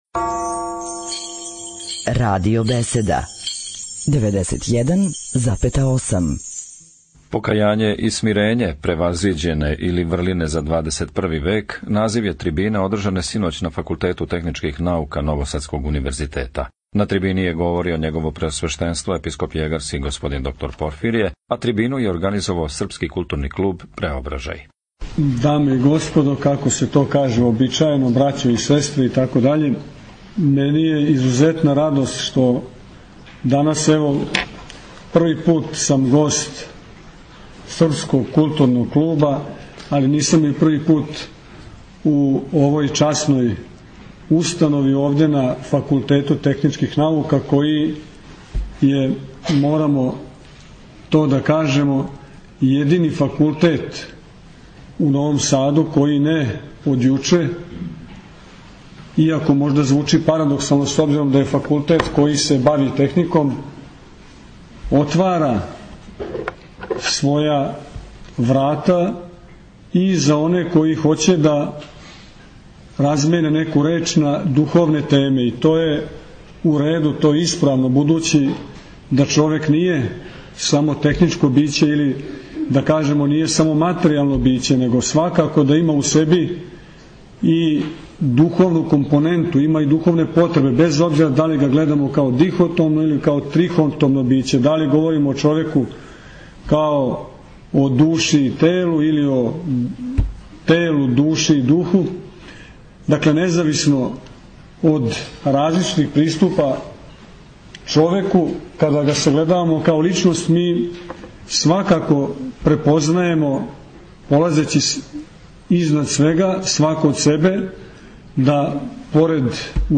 Пред многобројним слушаоцима у амфитеатру Факултета техничких наука у Новом Саду, 20. децембра 2010. године, Епископ јегарски Порфирије одржао је предавање на тему Покајање и смирење, превазиђење или врлине за 21. век.